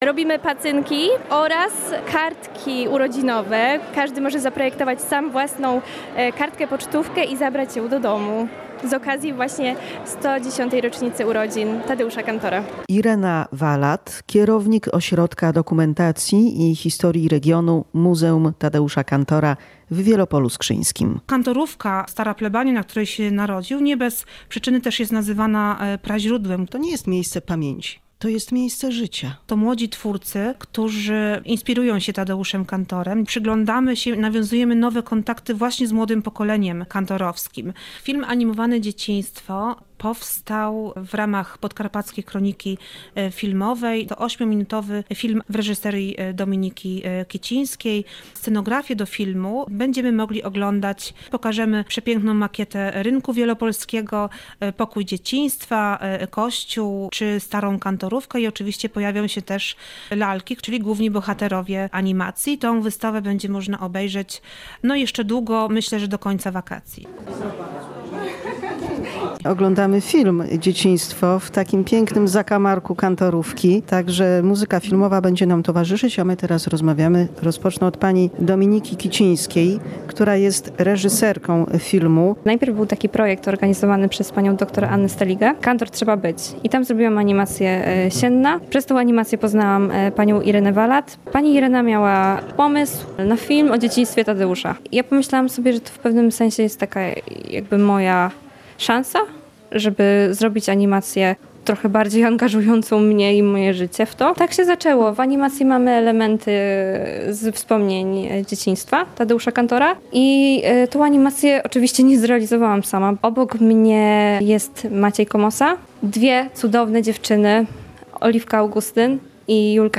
6 kwietnia w Wielopolu Skrzyńskim spotkali się badacze, pasjonaci i miłośnicy twórczości reżysera i scenografa. Wystawy, projekcja filmu animowanego, godziny rozmów i wymiana myśli.